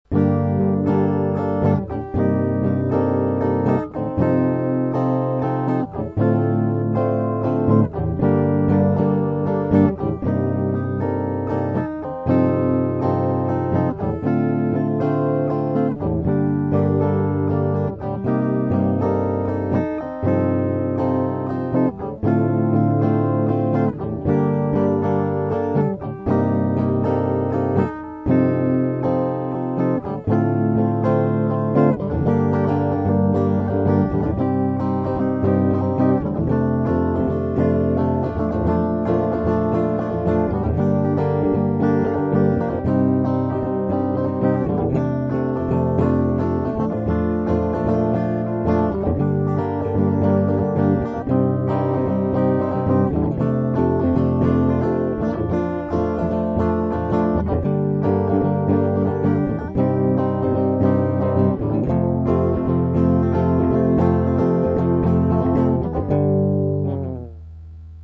mp3 - куплет и припев